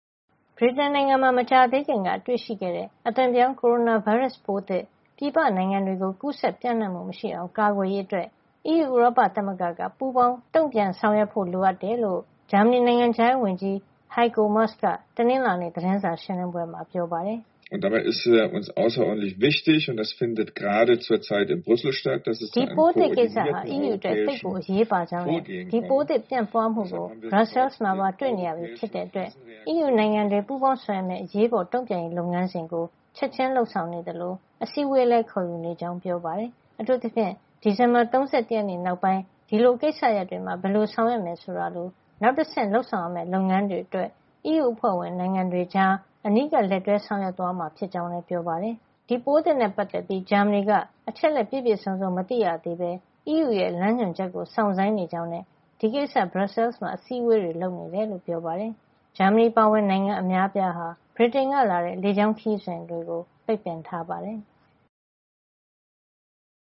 ဗြိတိန်နိုင်ငံမှာ မကြာသေးခင်ကမှ တွေ့ရှိခဲ့တဲ့ အသွင်ပြောင်း ကိုရိုနာဗိုင်းရပ်စ်ပိုးသစ် ပြည်ပနိုင်ငံတွေကို ကူးစက်ပြန့်နှံ့မှုမရှိအောင် ကာကွယ်ရေးအတွက် အီးယူဥရောပသမဂ္ဂက ပူးပေါင်းတုန့်ပြန်ဆောင်ရွက်ဖို့ လိုအပ်တယ်လို့ ဂျာမဏီ နိုင်ငံခြားရေးဝန်ကြီး Heiko Maas က တနင်္လာနေ့ သတင်းစာရှင်းလင်းပွဲမှာ ပြောပါတယ်။